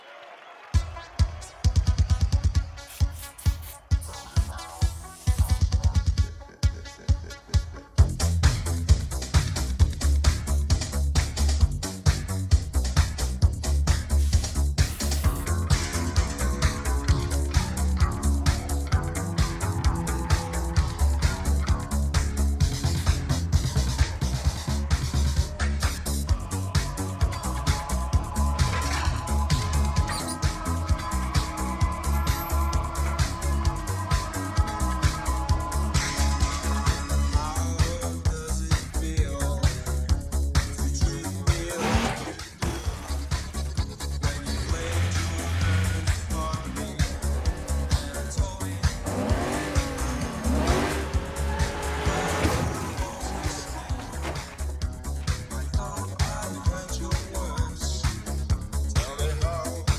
А вот обрамление все то ли синтетическое, то ли электрическое - что это? Вложения реф диско.mp3 реф диско.mp3 2,3 MB · Просмотры: 133